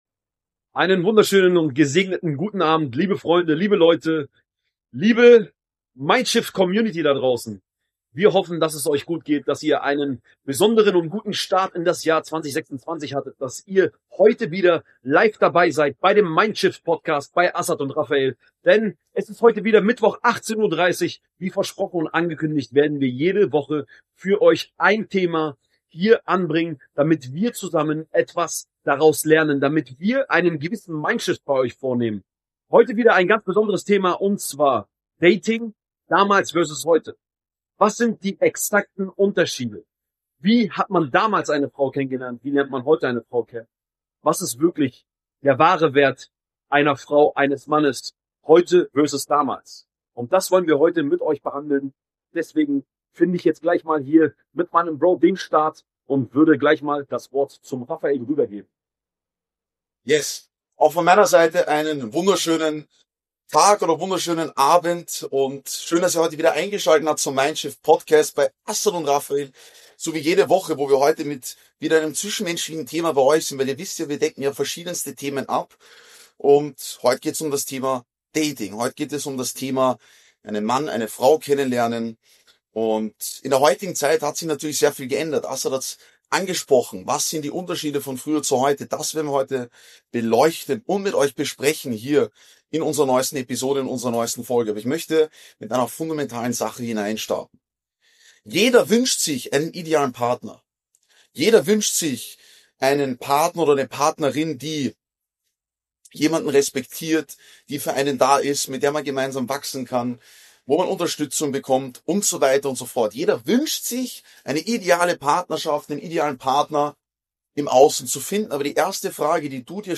- Sondern ein Real Talk über Liebe, Optionen, Ego und echte Verbindung im Jahr 2025.